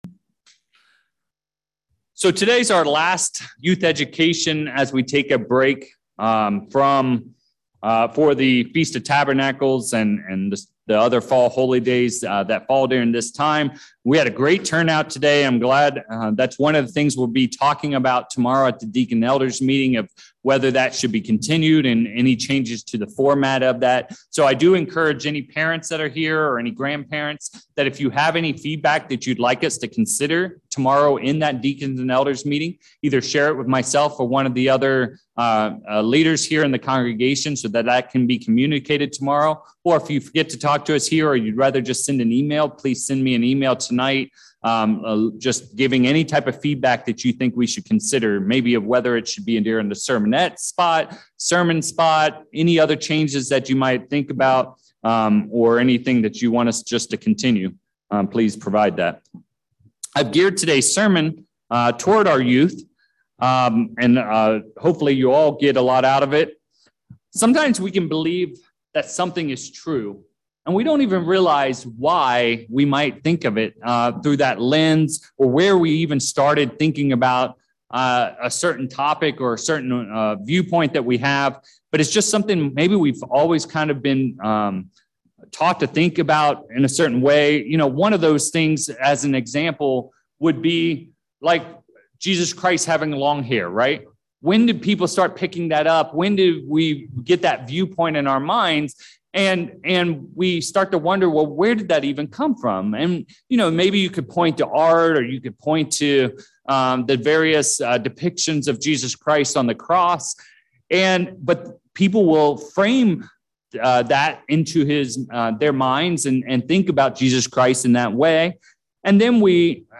8/20/22 How old do you have to be for God to work with and use you? In this sermon study, we’ll have fun speculating about the possible age of the disciples. We’ll also go through Paul’s specific instructions to young Pastor Timothy about how he must conduct himself so that others would not focus on his young age.